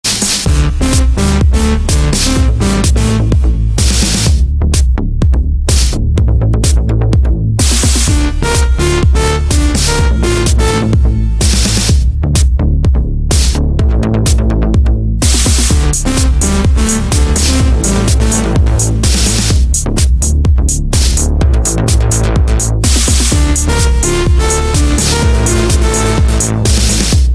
Relaxing Melodies for the Soul
is a gentle and deep chill-out song.